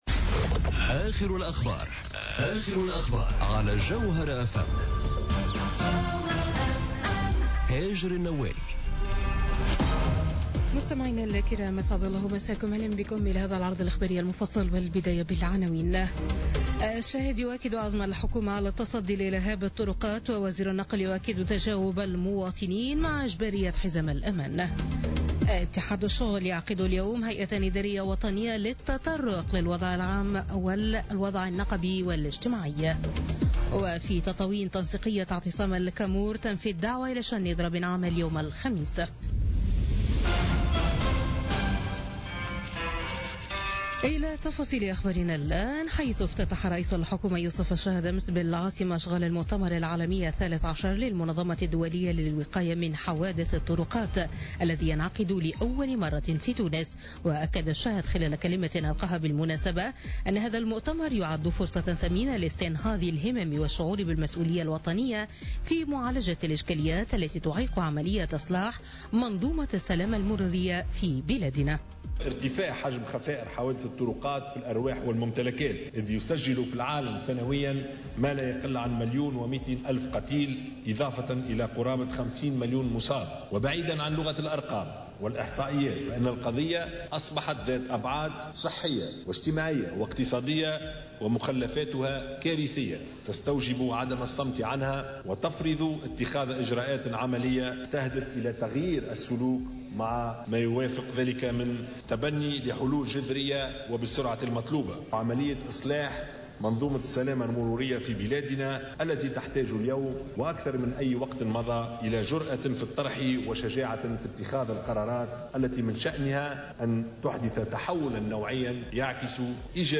نشرة أخبار منتصف الليل ليوم الخميس 4 ماي 2017